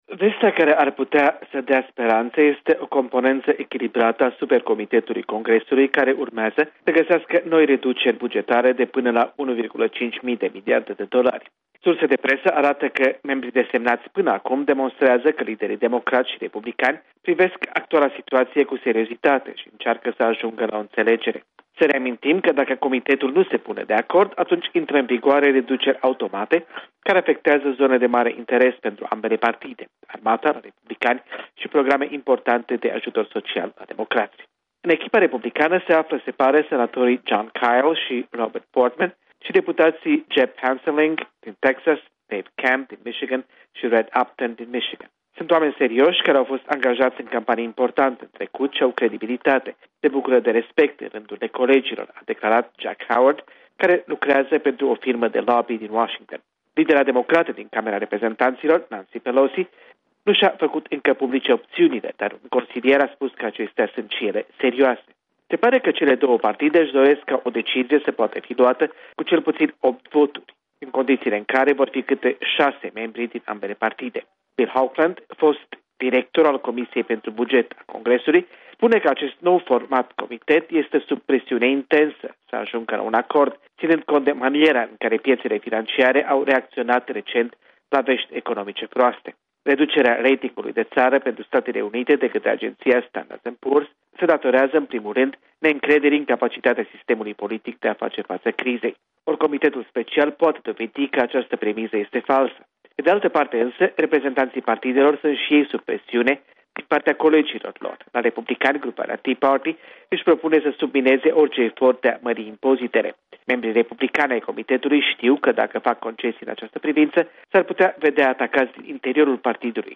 Corespondenţa zilei de la Washington